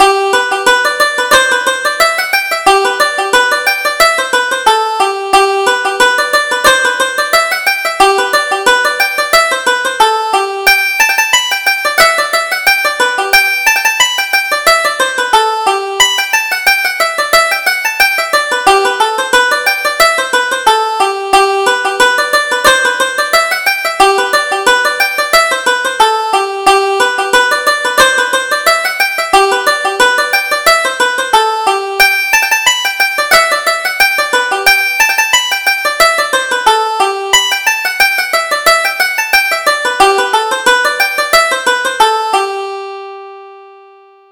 Reel: Tady's Wattle